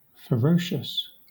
Ääntäminen
IPA : /fəˈɹəʊʃəs/
IPA : /fəˈɹoʊʃəs/